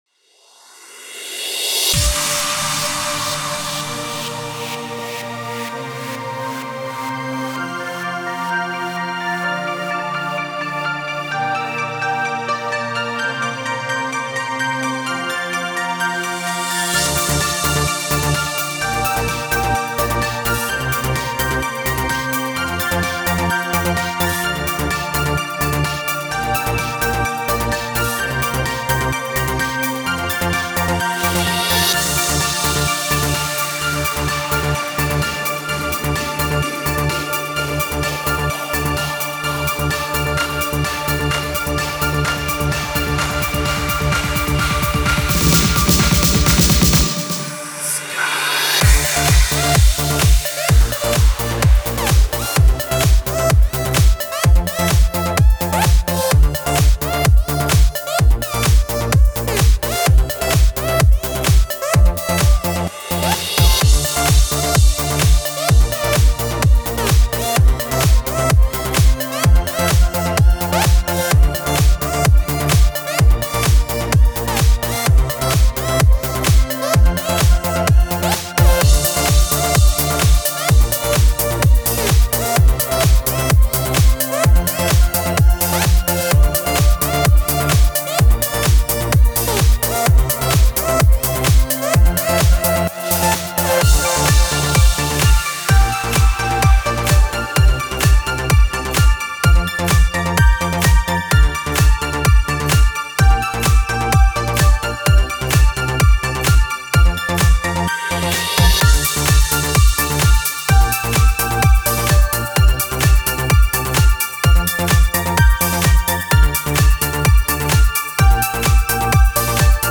Жанр: Electro